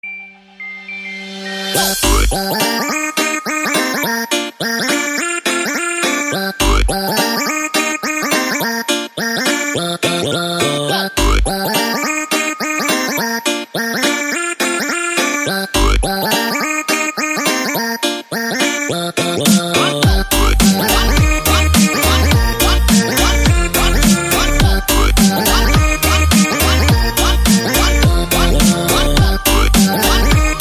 Categoría Electrónica